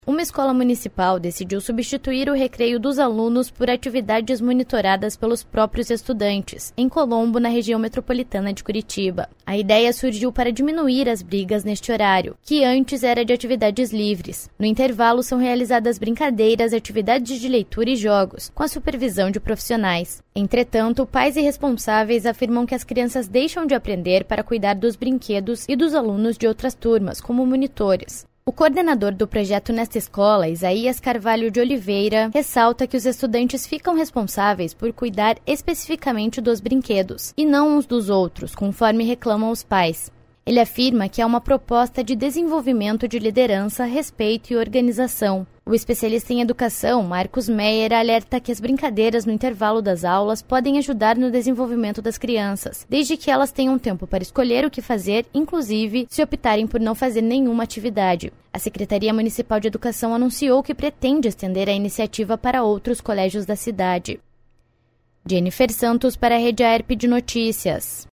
10.04 - BOLETIM SEM TRILHA - Escola de Colombo, na Grande Curitiba, substitui recreio por atividades monitoradas pelos alunos; pais discordam da iniciativa